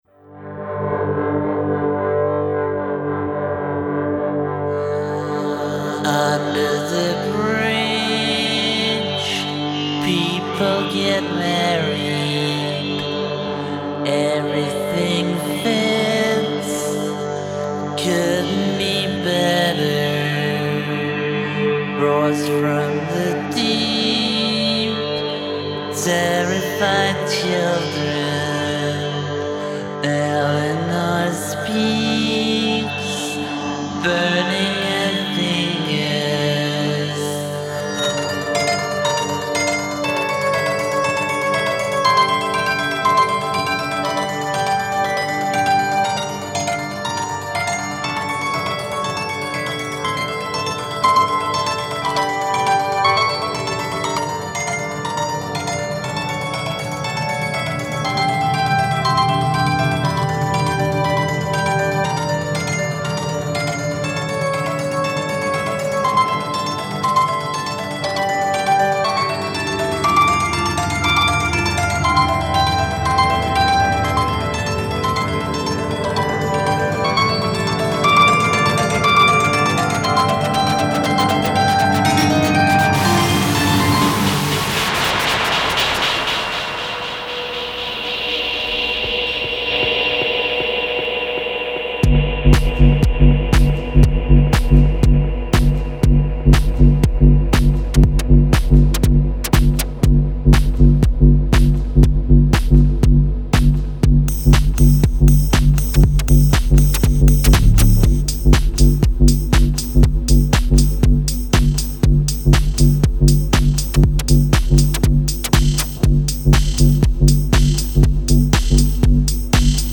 experimental pop quintet
cacophonic underwater pop